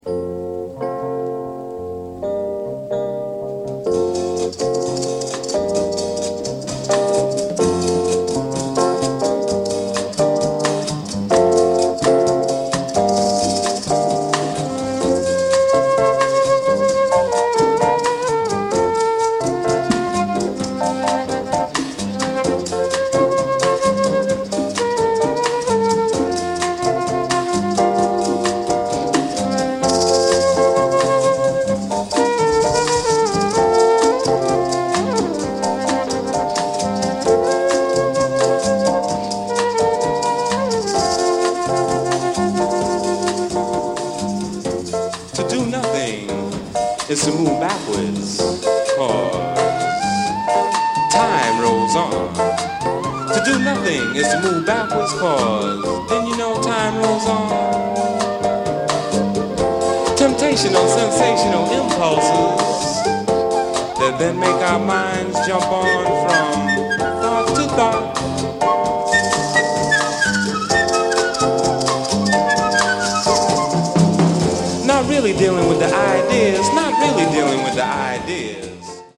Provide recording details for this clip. Recorded August 3rd, 1975 at the Oasis, Boston, Mass. Recorded February 3nd 1977 in Boston, Mass.